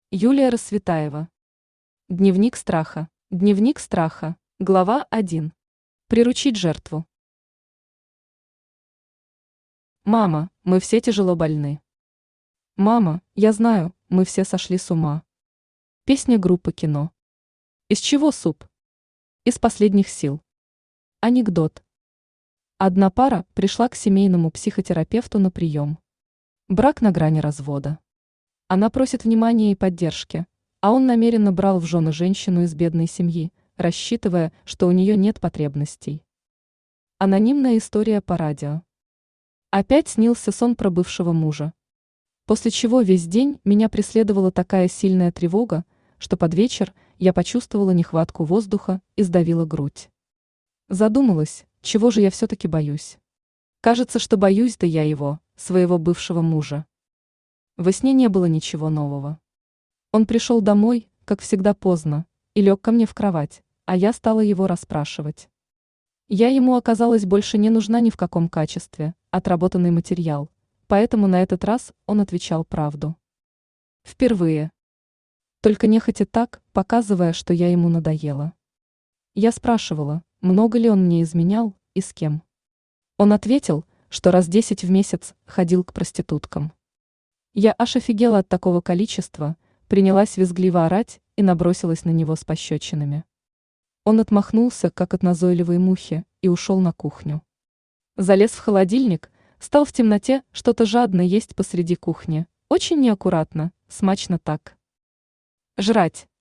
Аудиокнига Дневник Страха | Библиотека аудиокниг
Aудиокнига Дневник Страха Автор Юлия Рассветаева Читает аудиокнигу Авточтец ЛитРес.